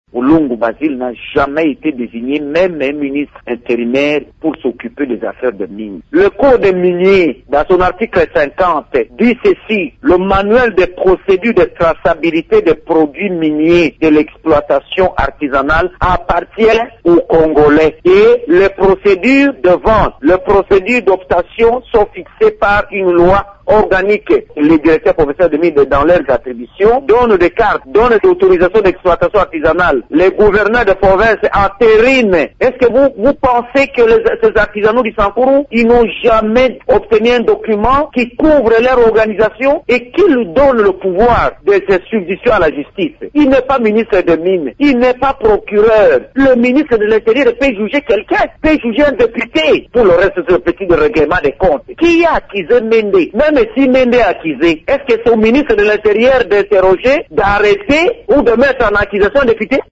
Justin Omokala, député provincial élu du Sankuru et porte-parole de Lambert Mende estime, dans une interview à Radio Okapi que le ministre intérimaire Basile Olongo n’a pas le droit de d’accuser Lambert Mende de détenir illégalement un diamant.